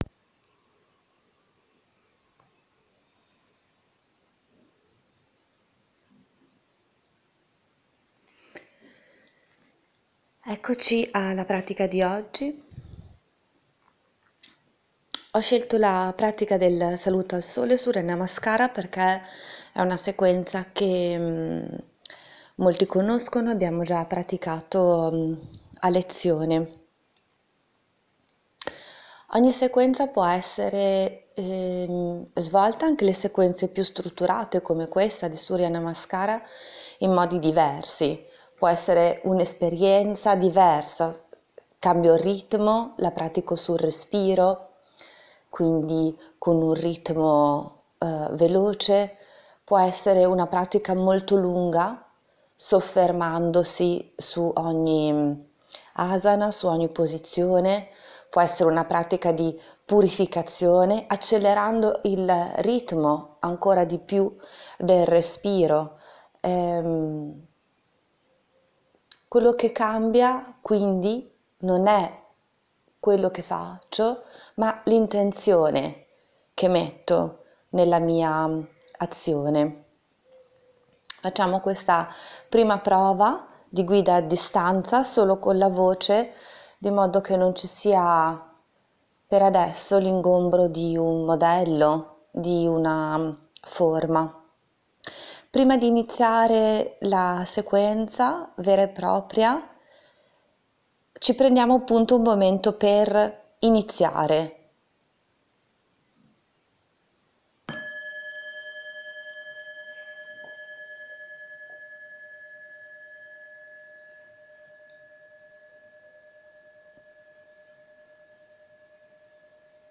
Di seguito, troverai l’audio per la guida della sequenza del saluto al sole e poi lo schema delle posizioni.